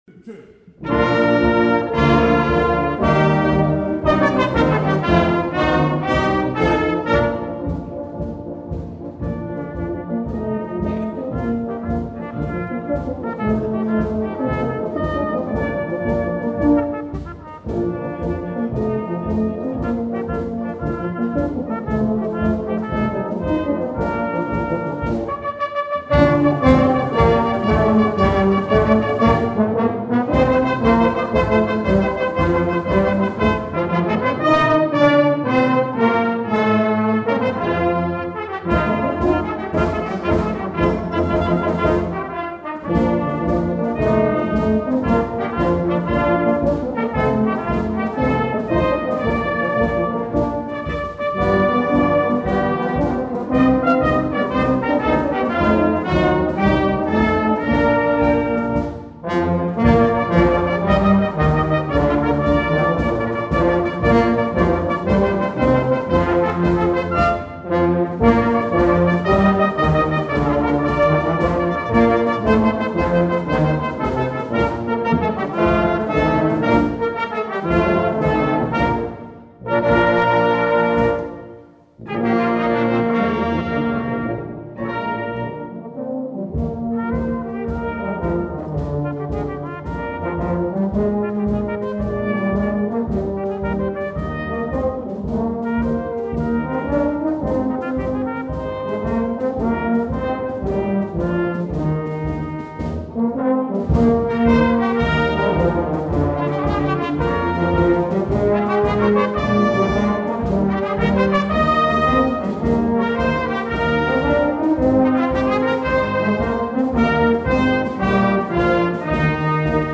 Some of the recordings were taken at live concert performances and may contain background noise and audience participation.